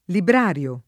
librario [ libr # r L o ]